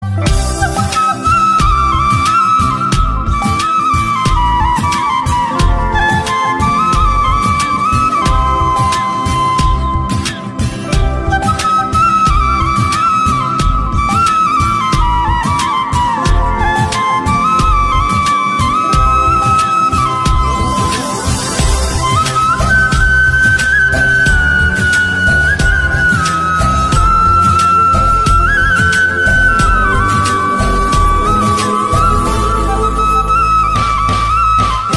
Flute Ringtone Hindi song